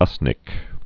(ŭsnĭk)